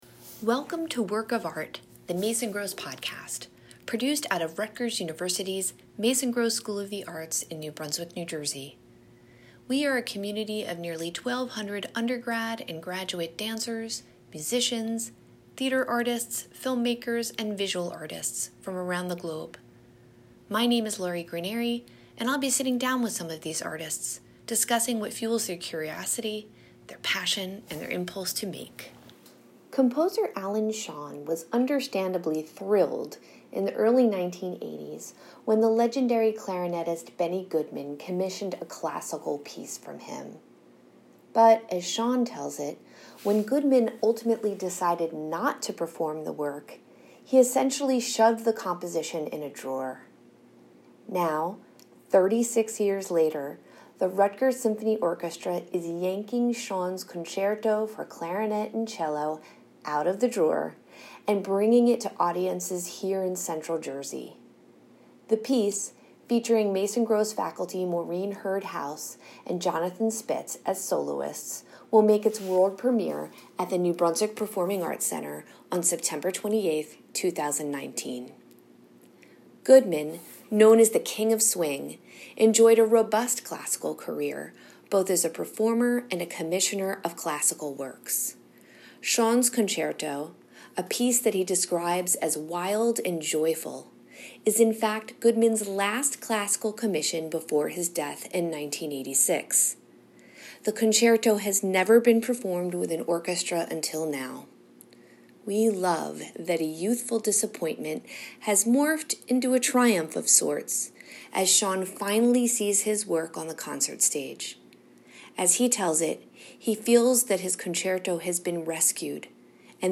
Every other Tuesday, we’ll speak with Mason Gross students, faculty, and guest artists, discussing what fuels their curiosity, their passion, and their impulse to make.